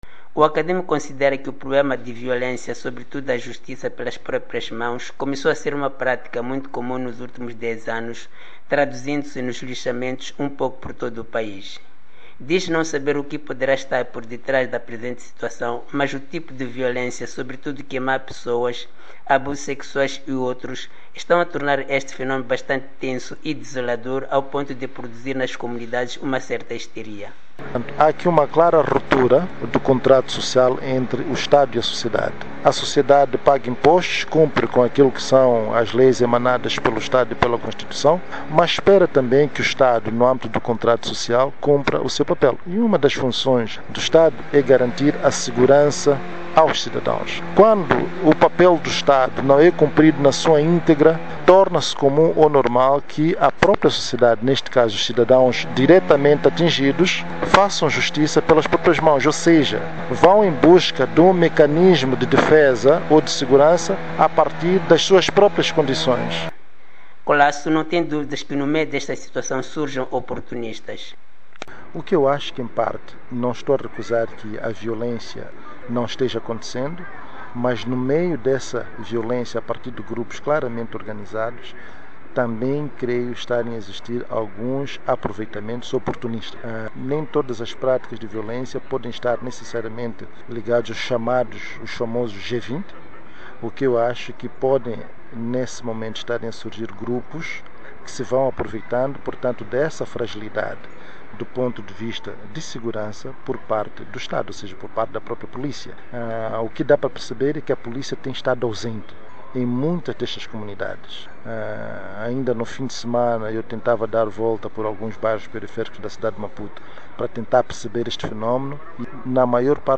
Numa entrevista à VOA